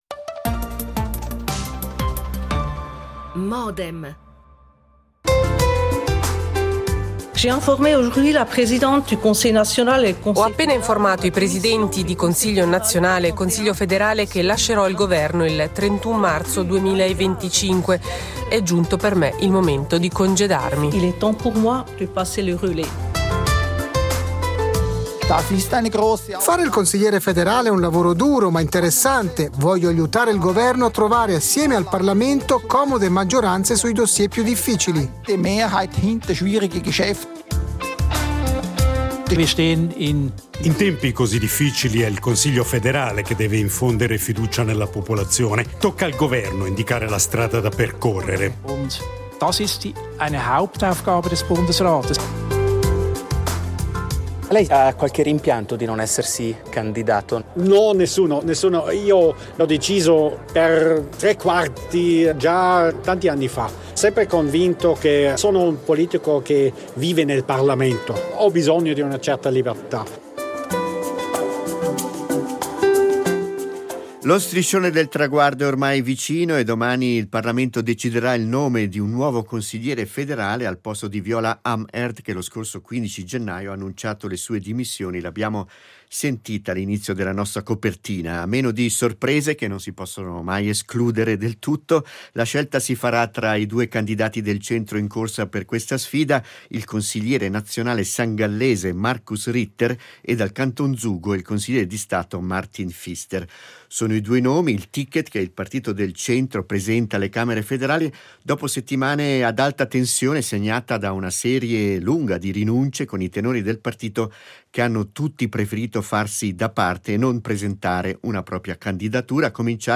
Qualche domanda al presidente Gerhard Pfister
L'attualità approfondita, in diretta, tutte le mattine, da lunedì a venerdì